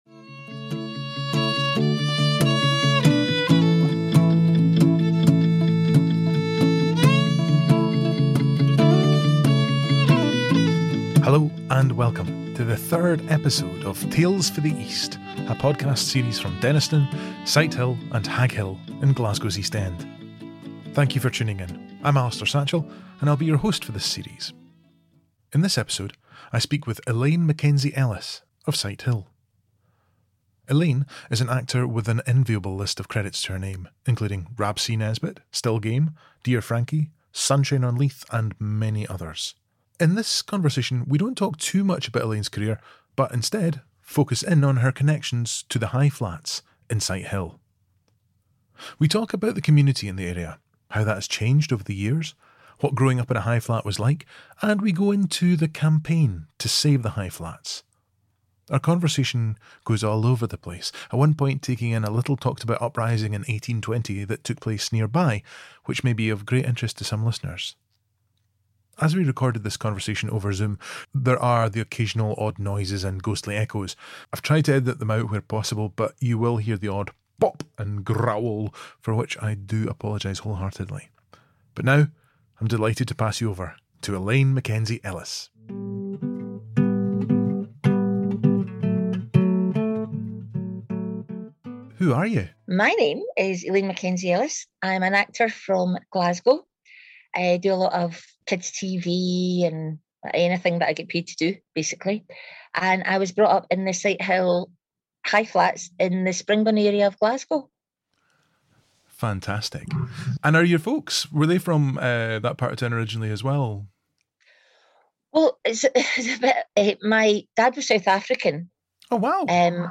As we recorded this conversation over Zoom there are occasional odd noises and ghostly echoes, I’ve tried to edit them out where possible, but you will hear the odd pop and growl for which I apologise.